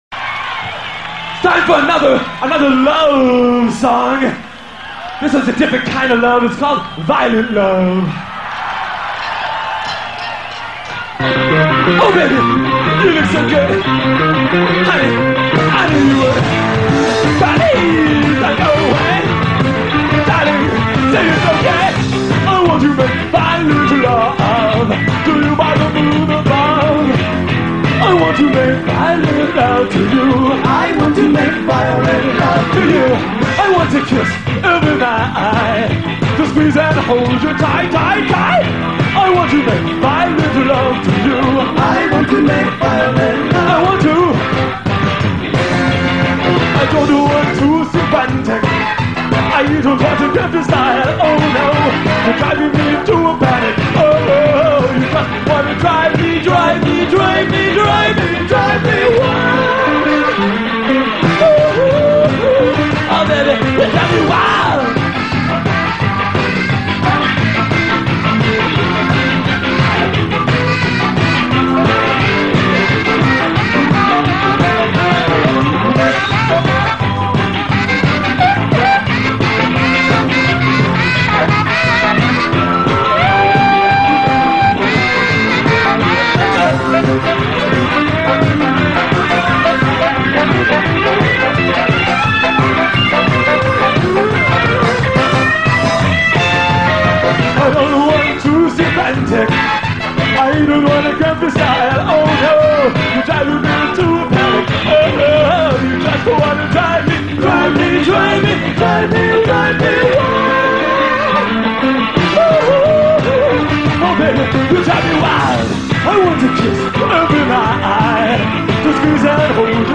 Ska cover